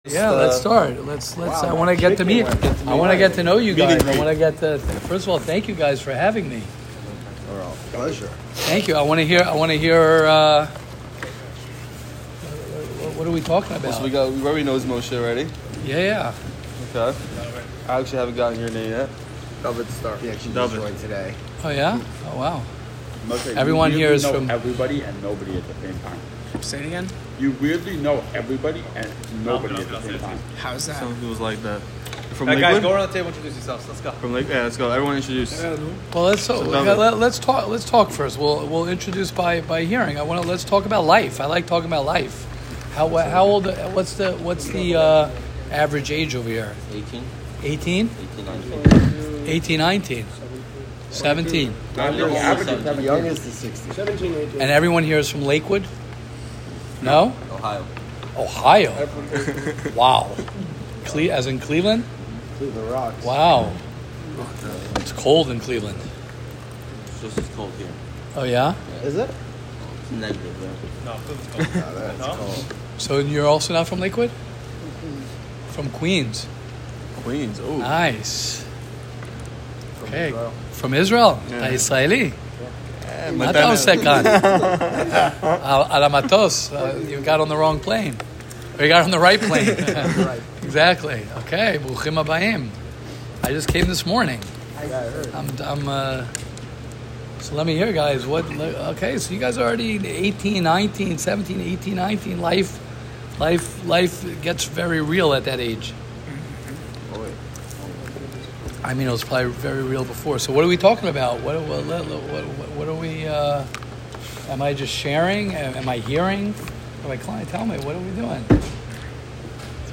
Q & A in Lakewood